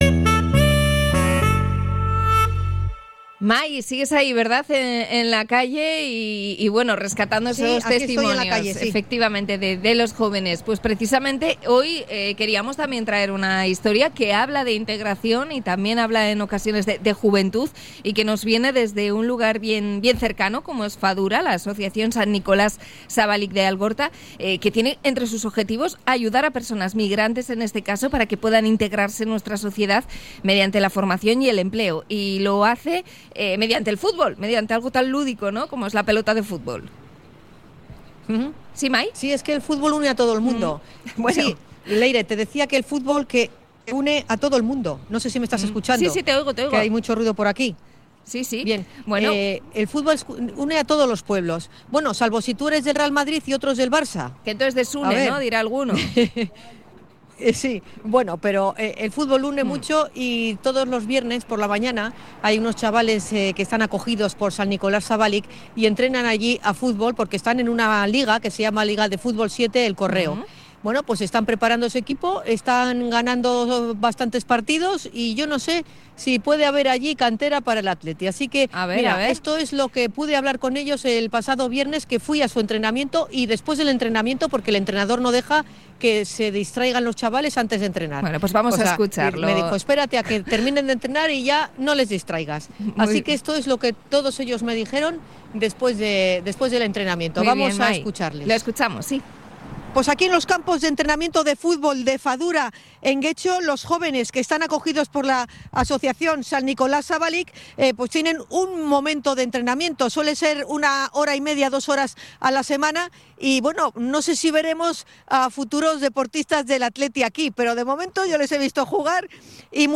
Hemos compartido una jornada de entrenamiento con los jóvenes migrantes